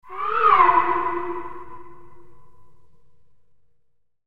【効果音】疑問・はてな・不思議＃２
アニメ風でコミカルな、疑問・はてな・クエスチョンなど不思議系な効果音素材バージョン２です。...